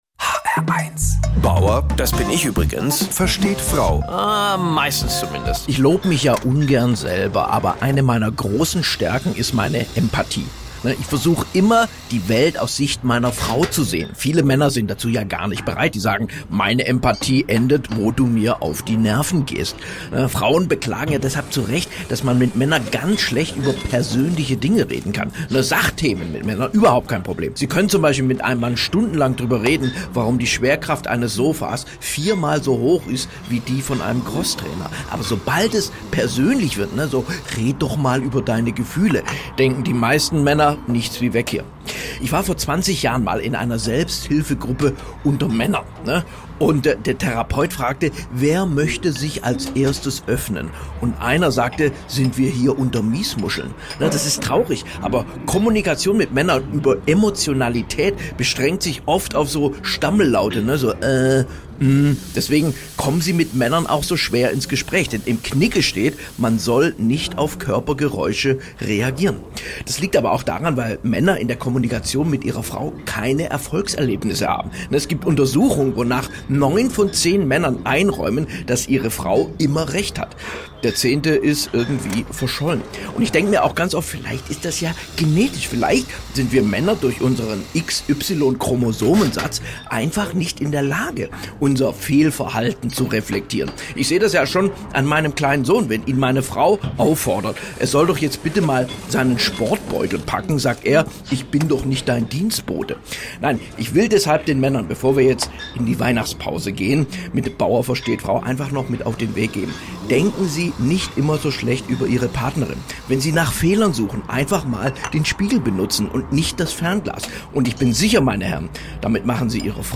Emotionale Männer zum Fest | Comedy